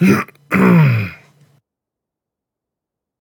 Grunt2.ogg